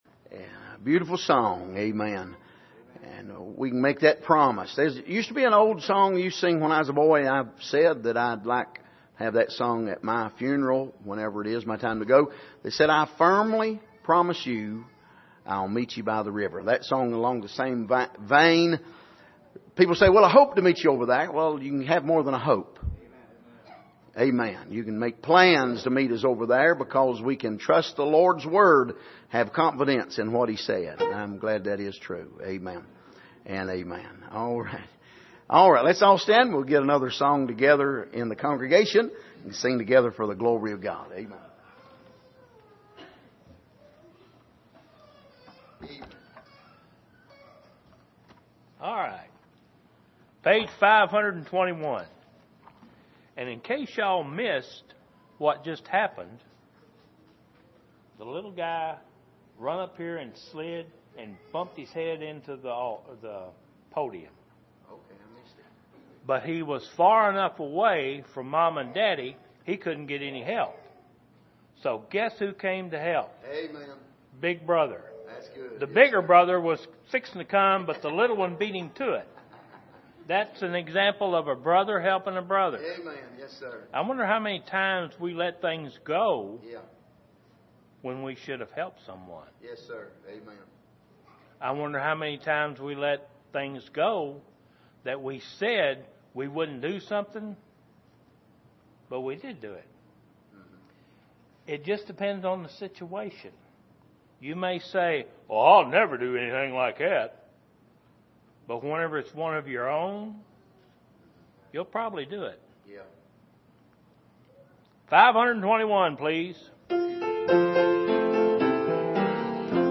Passage: Matthew 7:24-29 Service: Sunday Evening